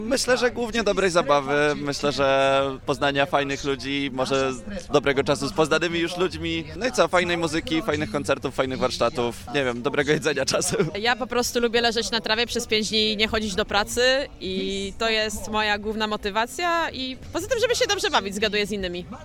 Co o festiwalu mówią uczestnicy ?